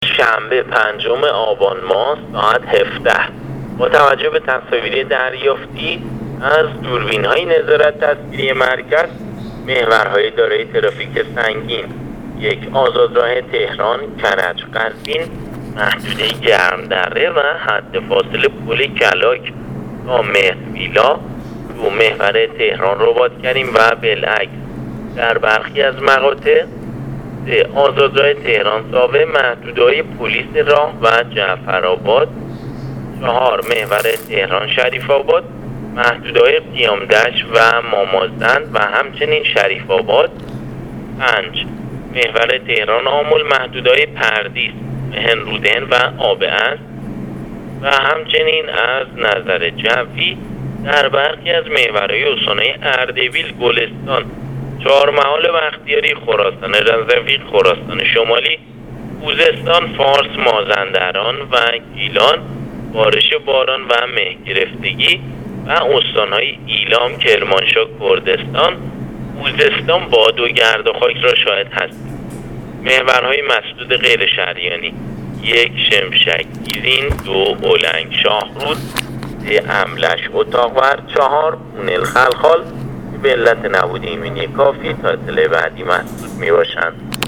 آخرین وضعیت محورهای مواصلاتی کشور را از طریق رادیو اینترنتی پایگاه خبری وزارت راه و شهرسازی بشنوید.